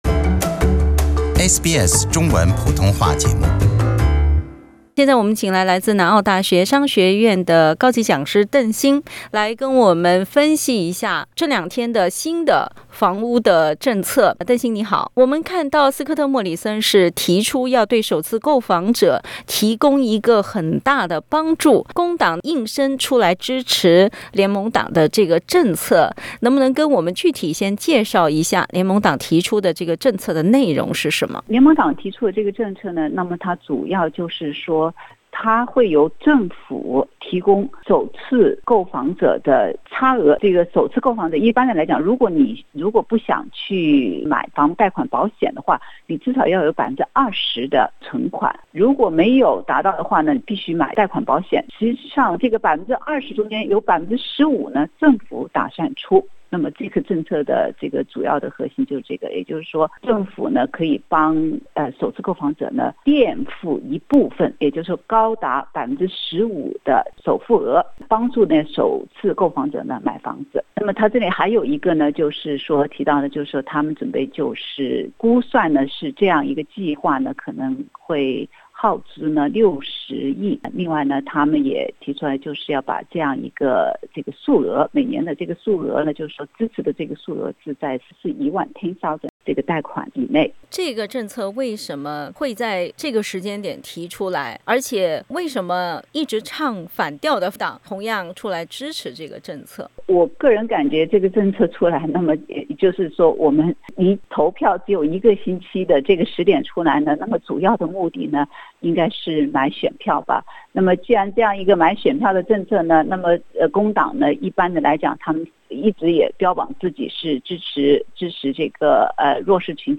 (AAP) Source: AAP SBS 普通话电台 View Podcast Series Follow and Subscribe Apple Podcasts YouTube Spotify Download (14.33MB) Download the SBS Audio app Available on iOS and Android 首次置业贷款首付计划能否起到买选票的作用？